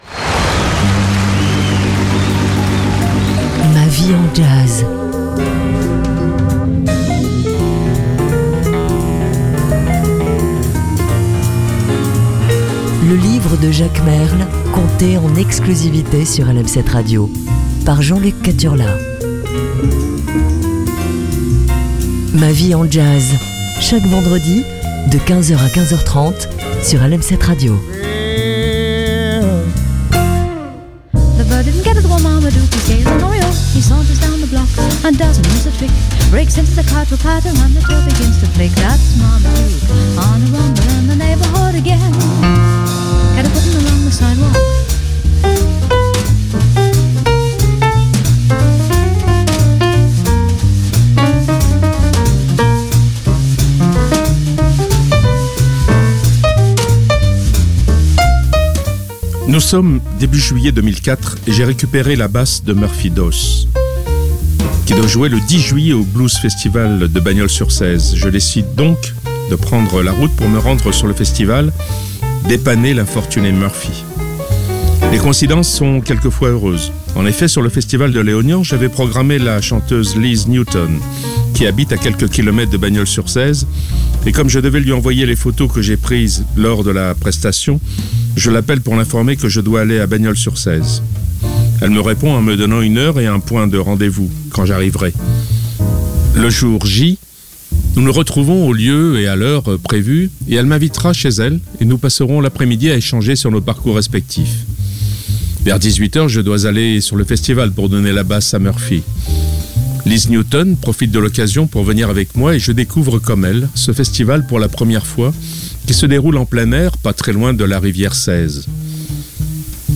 Les Vendredis sont très Jazzy sur LM7 Radio